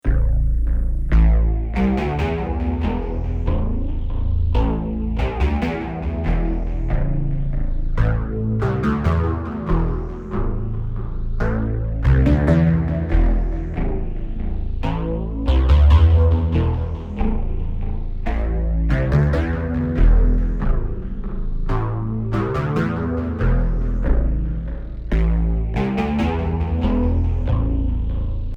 le son avec fx (un ASR-10 : trop facile !-)